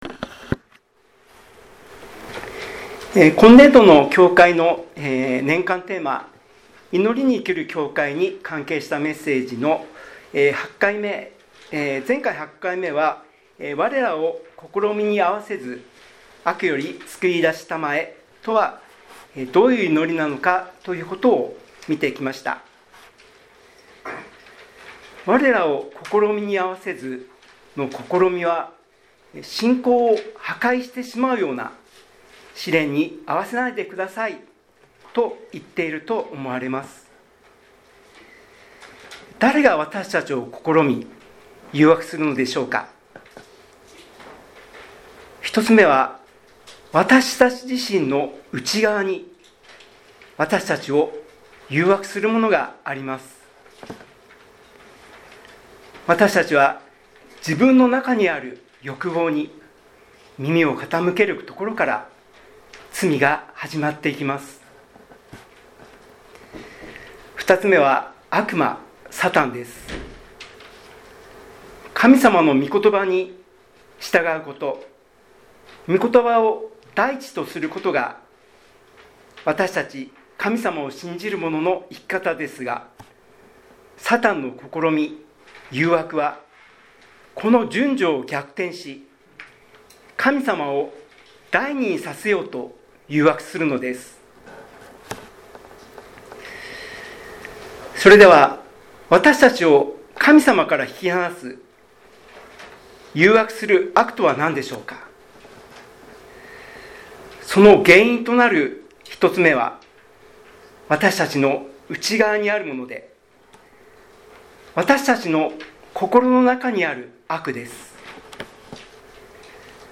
2025年12月7日聖日 メッセージ